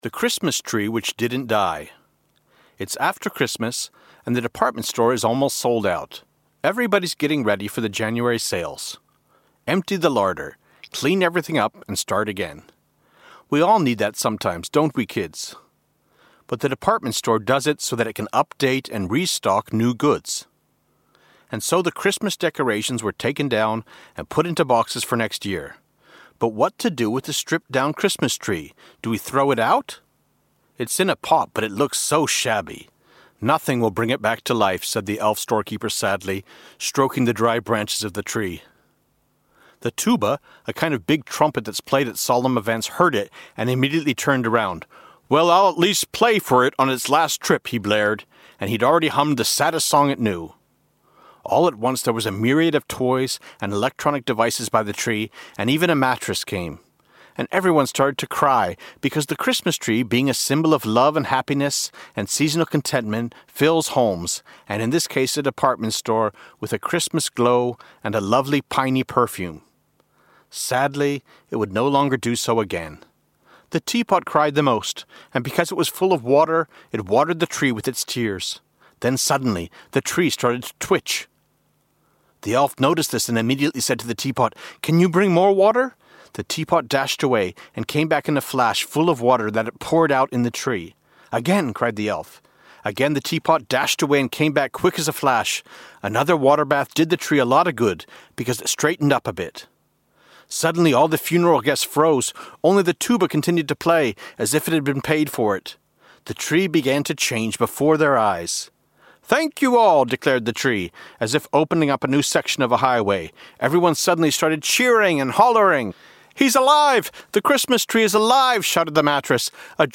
Audiobook The Elf Storekeeper written by Vladimír Mráz.
Ukázka z knihy